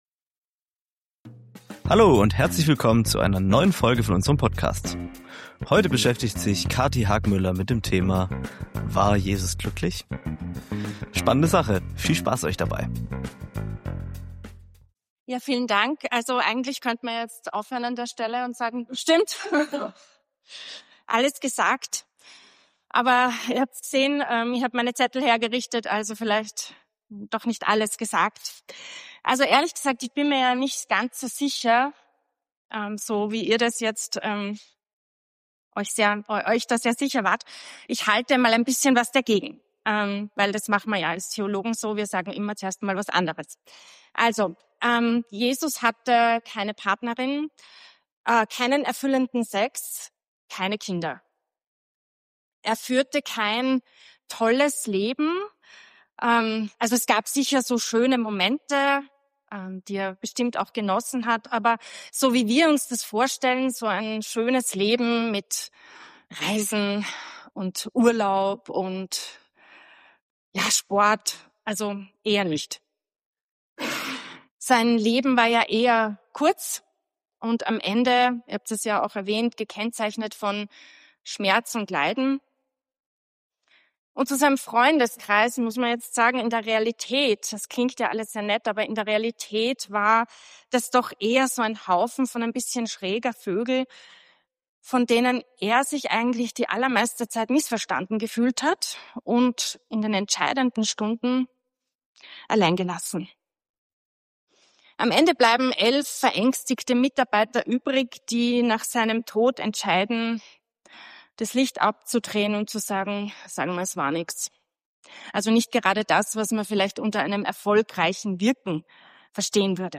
Predigen Podcast